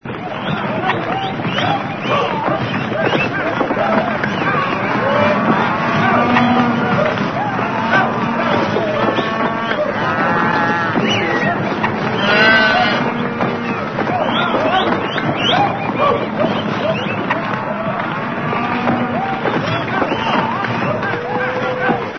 Descarga de Sonidos mp3 Gratis: conduciendo ganado.
COW3.mp3